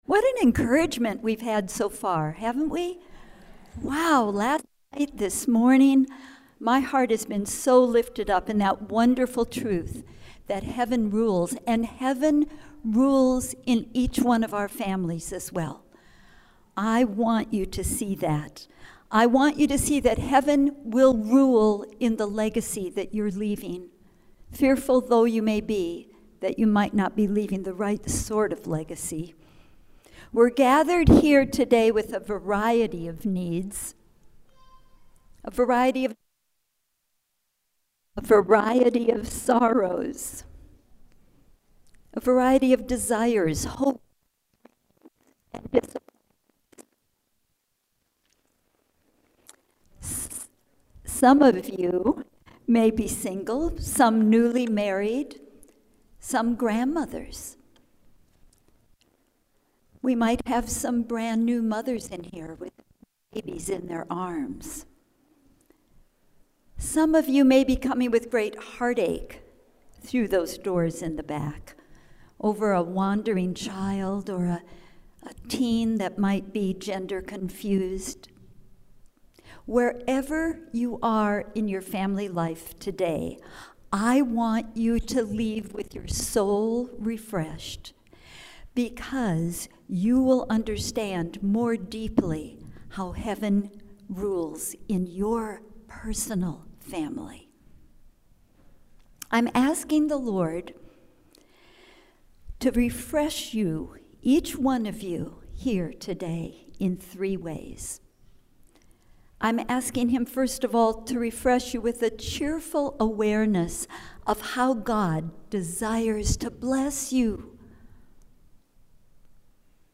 Raising a Christian family is both thrilling and sobering. This breakout will explore what the children in your life need and how the Lord will enter in as you help them to “set their hope in God” (Psalm 78:7).